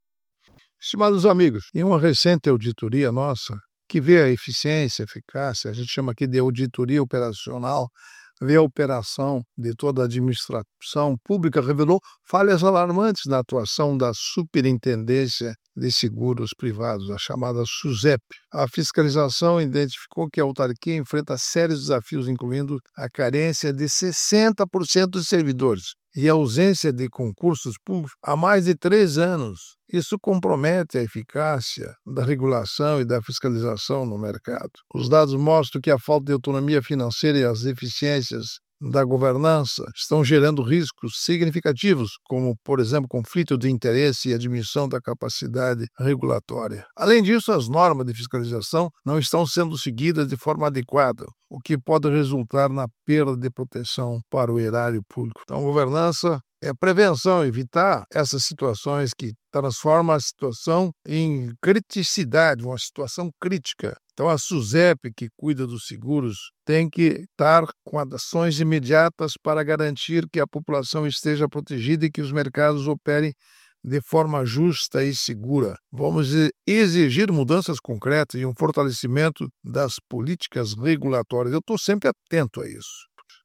Comentário de Augusto Nardes, ministro do TCU.